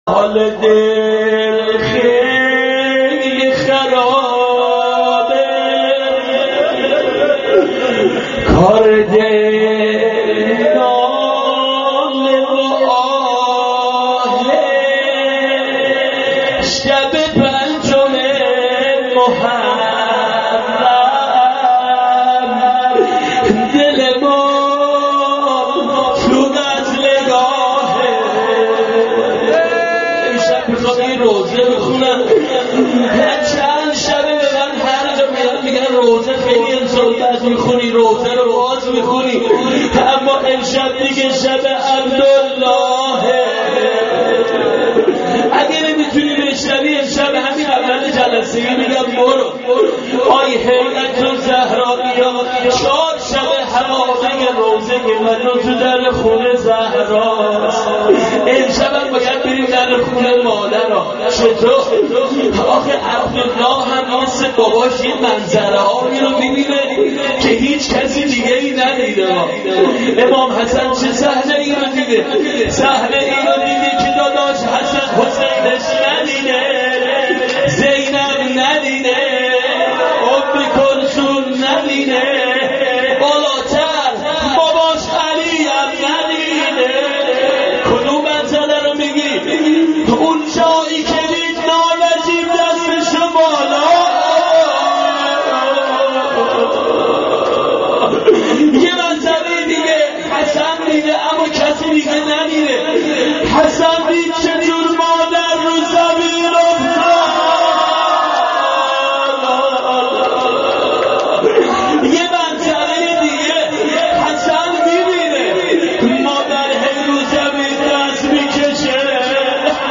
شب پنجم محرم دل ما تو قتلگاهه-----روضه حضرت زهرا و عبدالله بن حسن و قتلگاه اباعبدالله.MP3